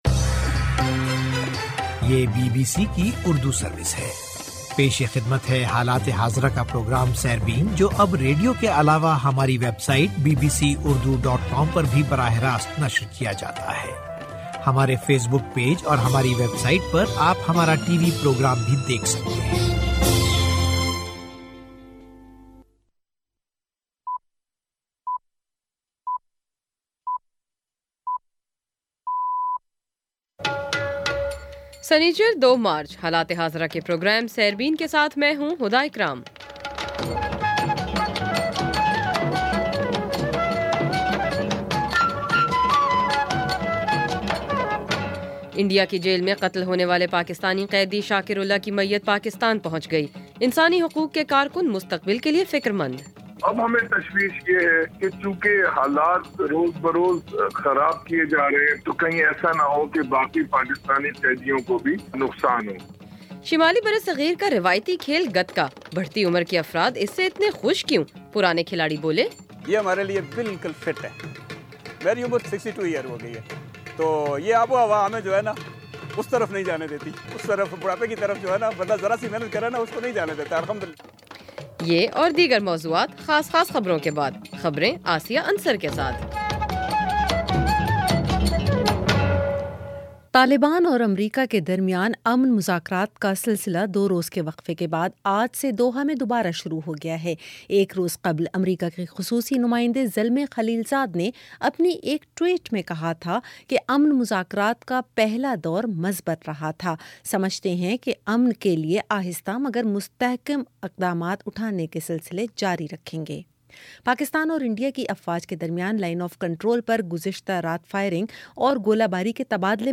سنیچر 02 مارچ کا سیربین ریڈیو پروگرام